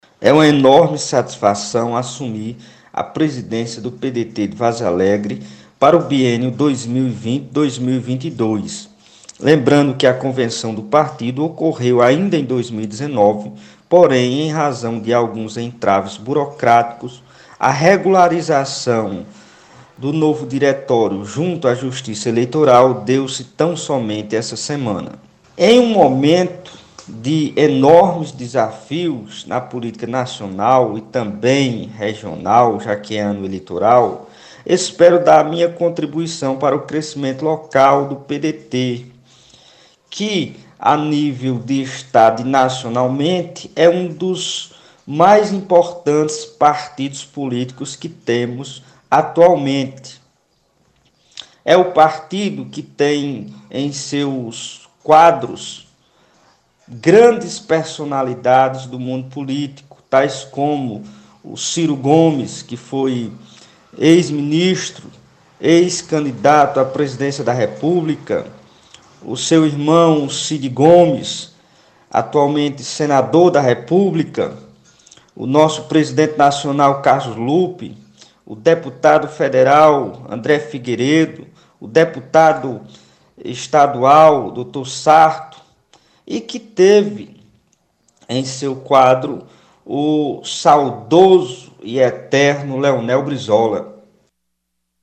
À reportagem ele fala da novidade: https